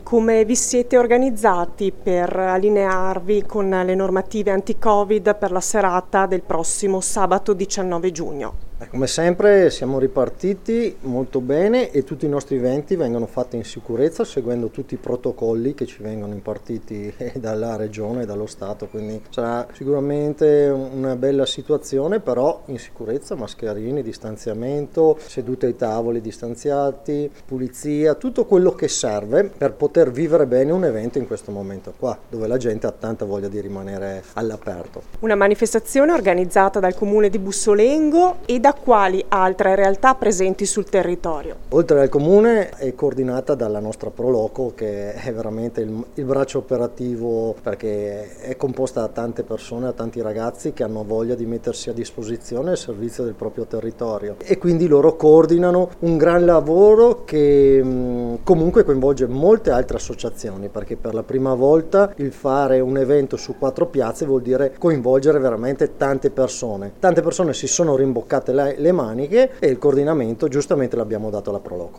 L’Assessore alle Manifestazioni Massimo Girelli al microfono della nostra corrispondente
massimo-girelli-assessore-bussolengo.mp3